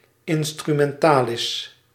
Ääntäminen
US : IPA : [ˌɪn.stɹə.ˈmɛn.təl]